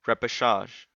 Repechage (/ˌrɛpɪˈʃɑːʒ/
En-us-repechage.ogg.mp3